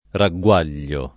ragguaglio [ ra ggU# l’l’o ] s. m.; pl. -gli